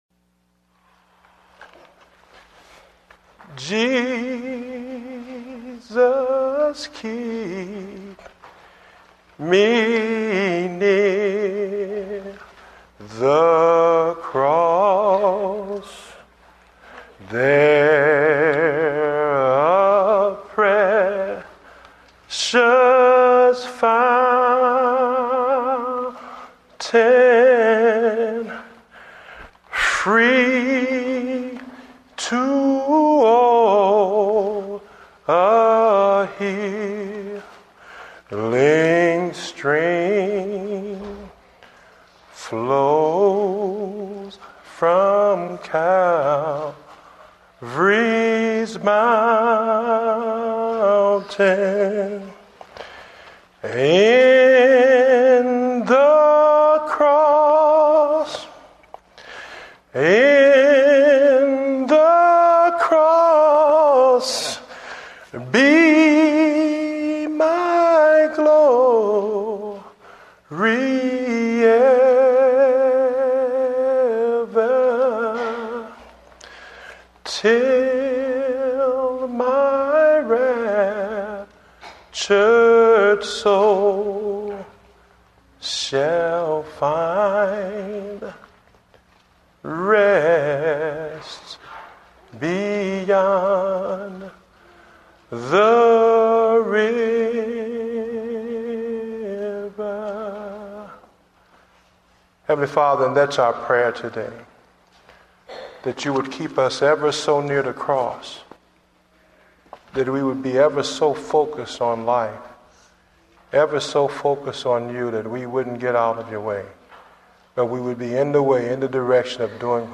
Date: October 18, 2009 (Morning Service)